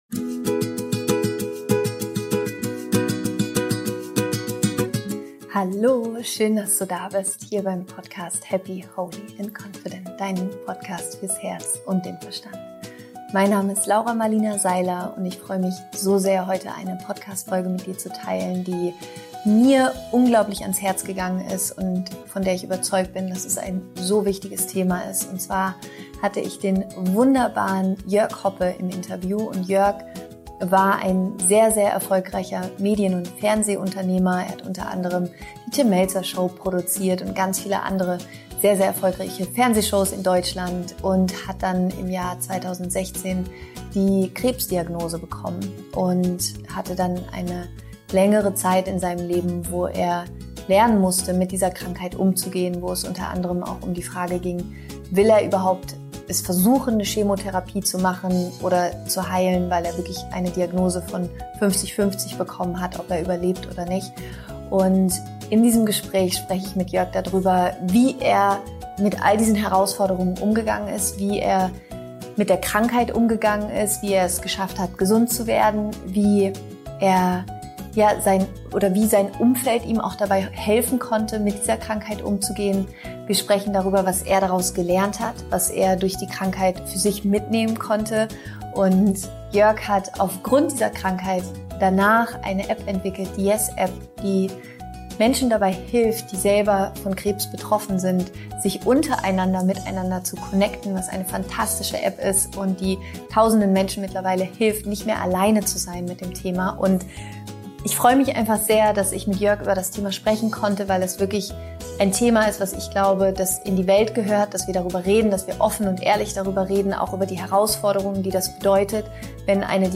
Warum eine Krise immer eine Chance ist, sich neu zu erfinden – Interview Special